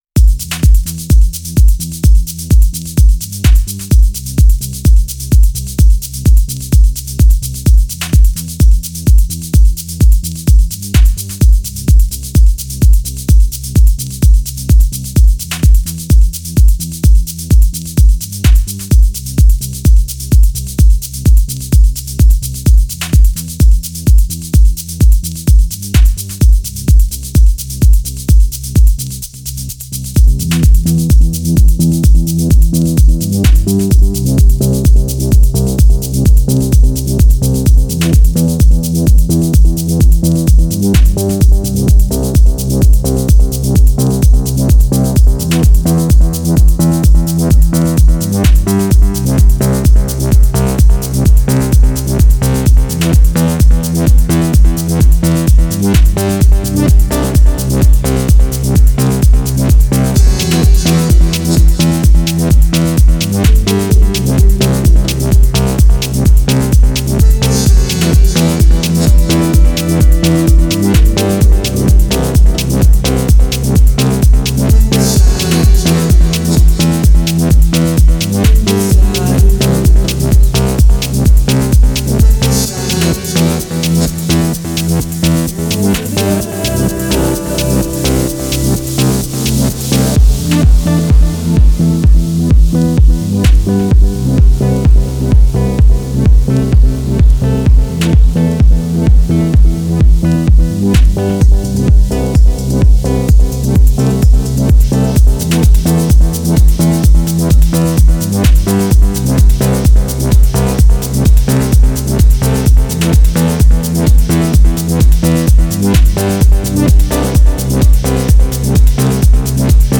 Стиль: Progressive House / Deep Techno